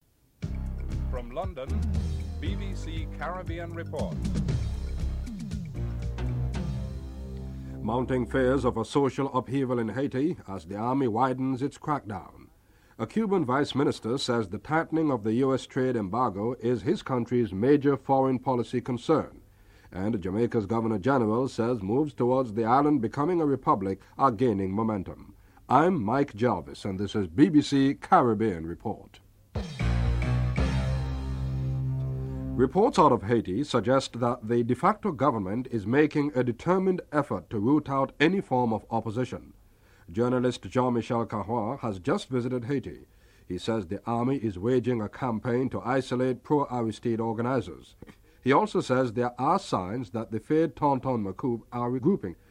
1. Headlines (00:00-00:32)
In an interview with Correspondent Brian Redhead she talks of her expectation for improvement in British aid disbursement (12:13-14:39)